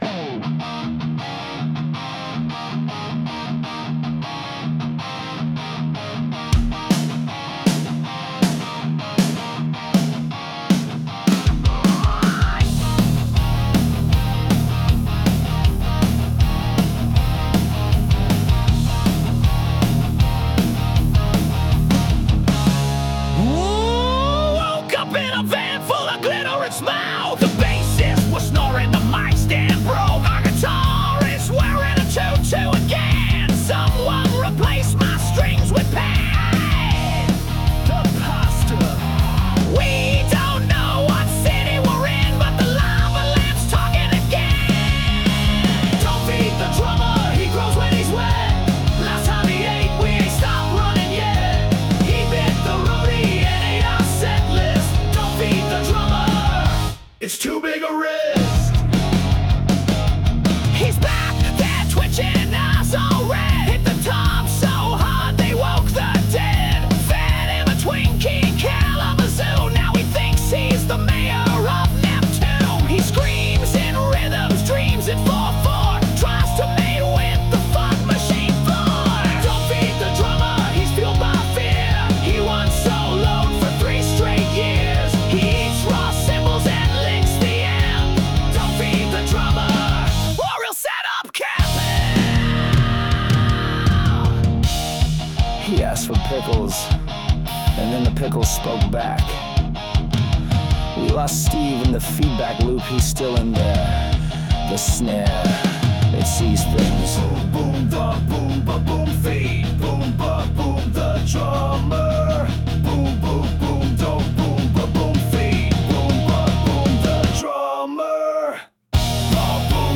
Rock Band, Bass Ackwards!